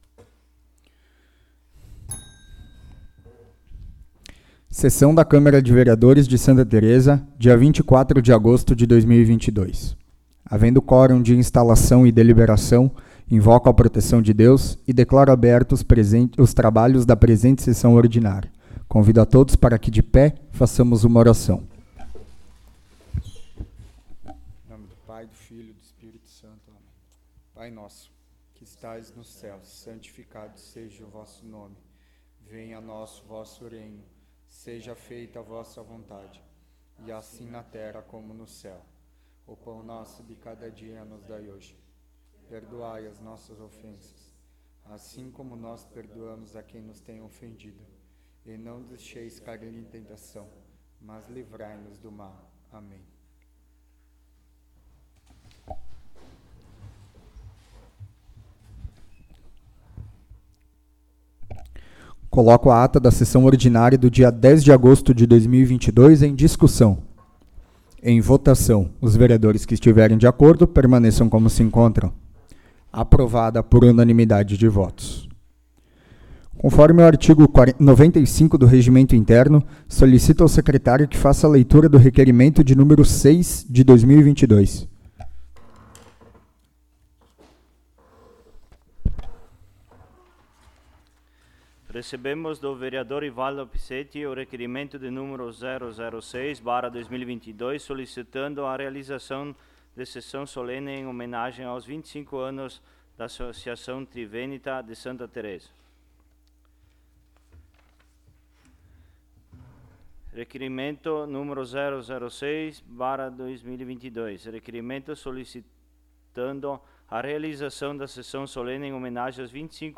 14ª Sessão Ordinária de 2022